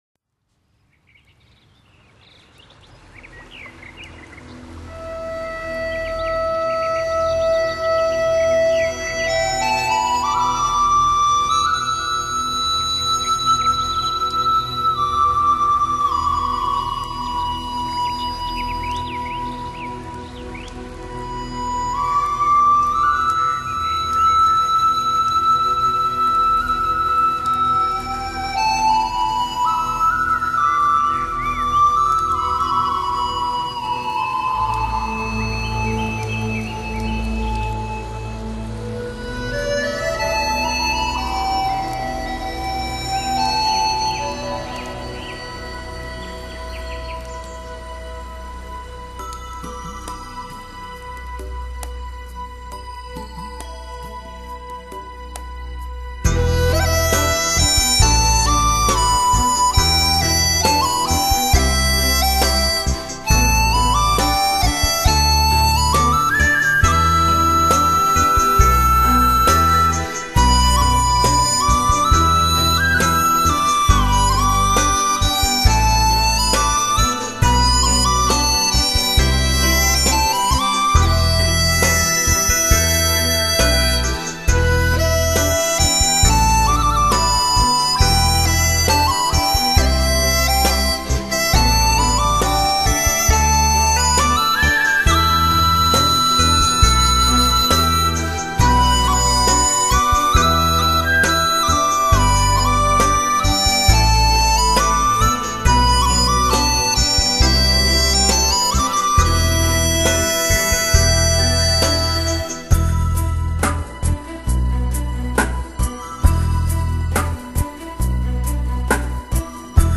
笛子演奏